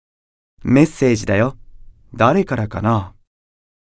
Solomon_Chat_Notification_Voice.ogg.mp3